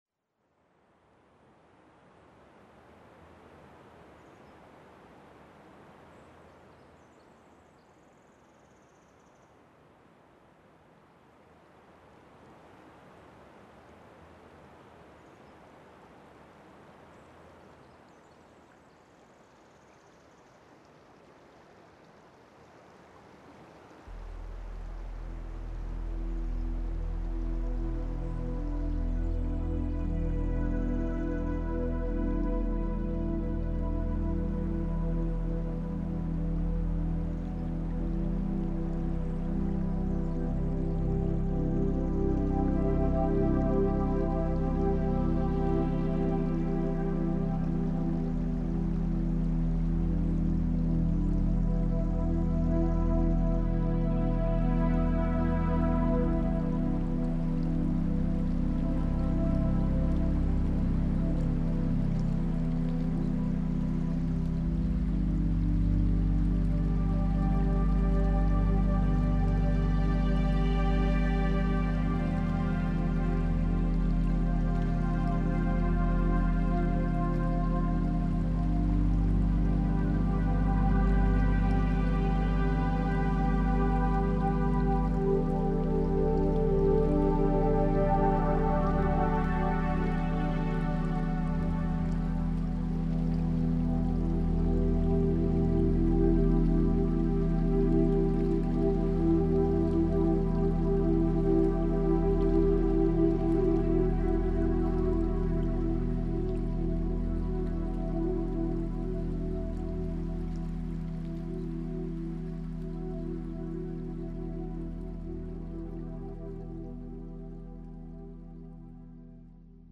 Žodžiu vedama meditacija